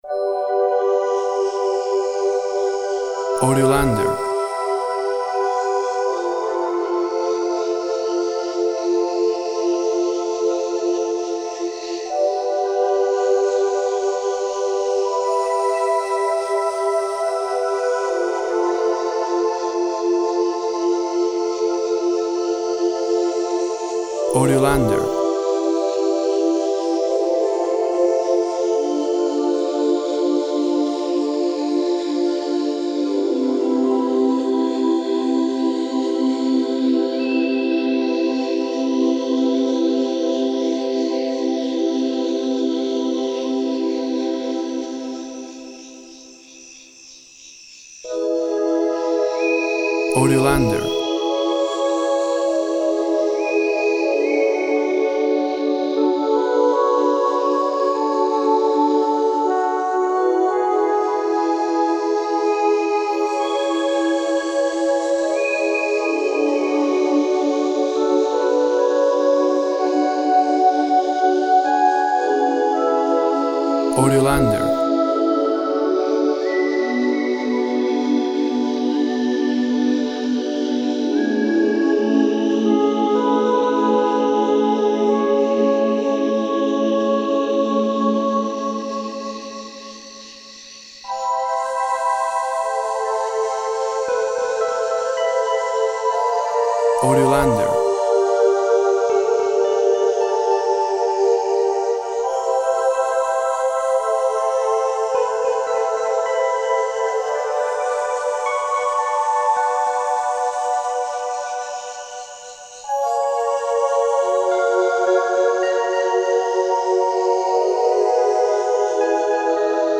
Gentle sounds of the night.
Tempo (BPM) 48